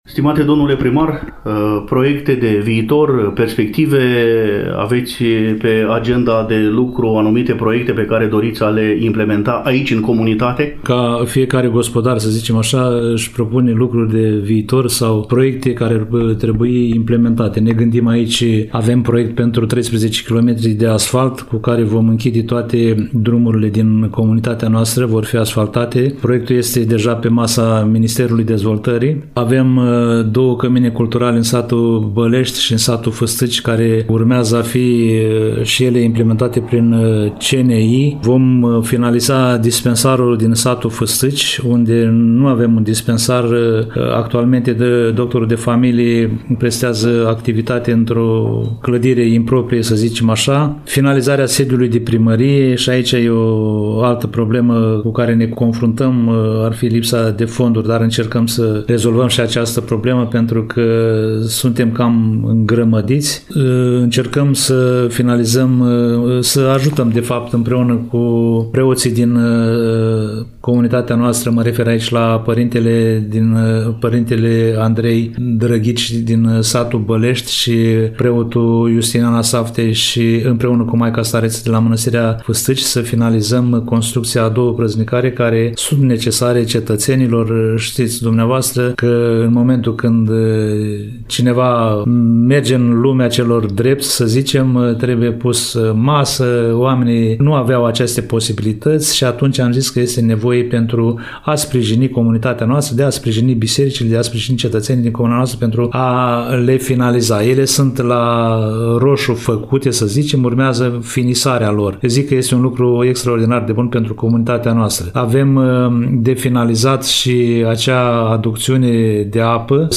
Primul interlocutor al ediției este domnul Hristache Sima, edilul șef al comunei. În următoarele minute aflăm detalii despre câteva proiecte pe care administrația locală le are pe agenda de lucru; aproape de finalul dialogului, atât pentru cetățenii comunei, cât și pentru ascultătorii Radio Iași, edilul șef al comunei Cozmești are câteva mesaje.
AA_Sima-Hristache-dialog-4-30.mp3